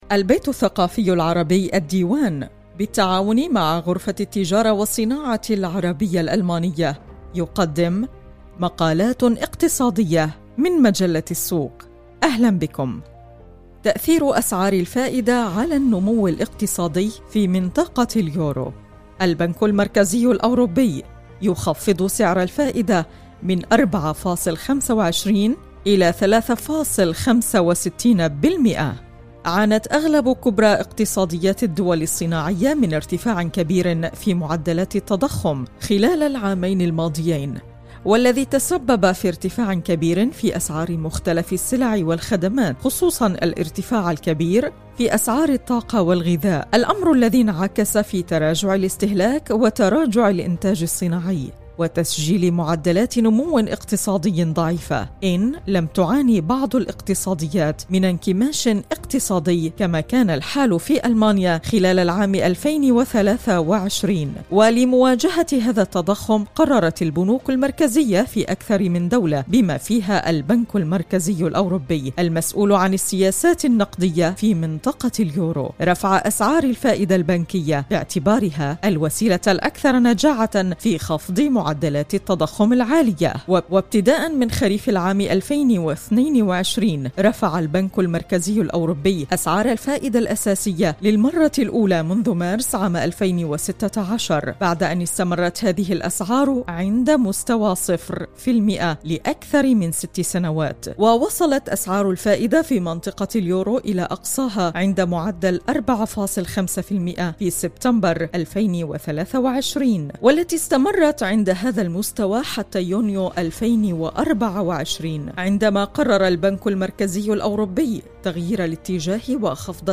Die Gespräche mit den Experten bieten interessante Einblicke in die wirtschaftlichen Entwicklungen und Trends. Der Austausch im Podcast über wirtschaftliche Analysen soll die Möglichkeit geben, die eigene Perspektive auf die deutsche Wirtschaft zu schärfen und komplexe Sachverhalte besser zu verstehen.